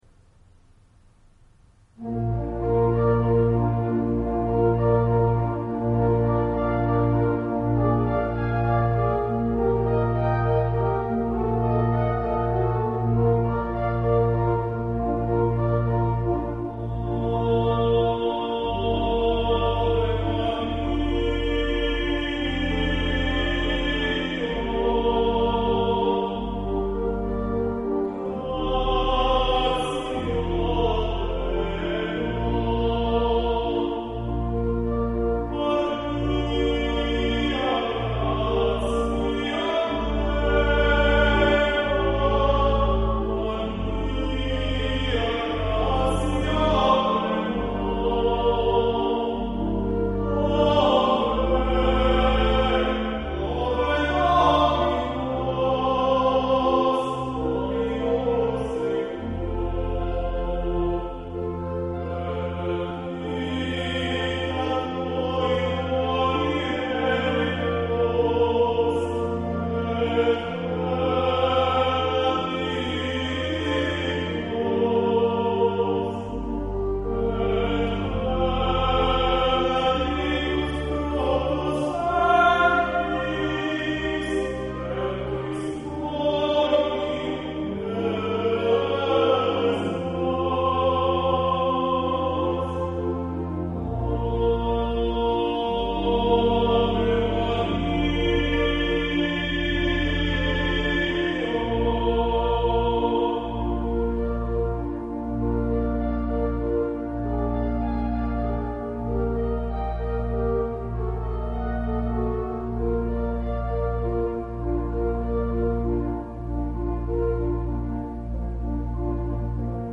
Ave Maria (Franz Schubert) (with Pipe Organ)
Ave-Maria-Franz-Schubert-with-Pipe-Organ.mp3